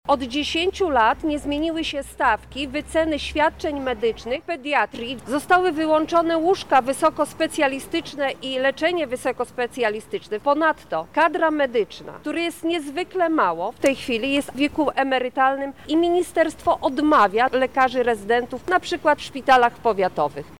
-mówi posłanka Koalicji Obywatelskiej Marta Wcisło.